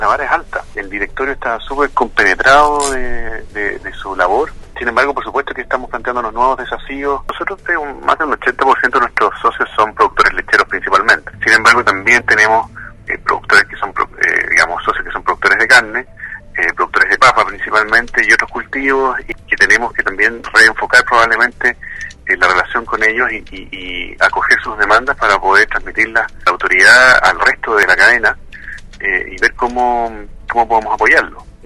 En dialogo con Radio Sago el Gerente de Agrollanquihue, Rodrigo Mardones junto con manifestar su satisfacción por este nuevo desafío señaló que pretende trabajar de la mano con el Directorio y los socios que trabajan en diferentes rubros, en su mayoría leche, carne, berries, entre otros, y así afrontar las diversas problemáticas que hoy en día deben enfrentar los productores en Chile.